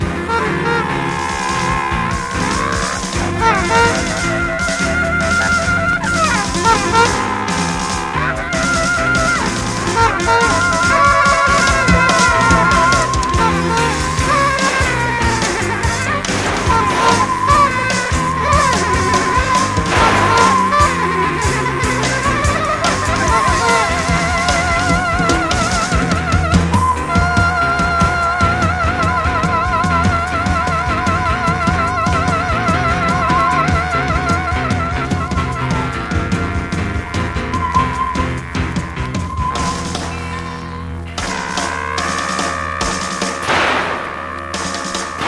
MEDIA:VG PVCアウターによるクモリ/ヤケあり 試聴でご確認ください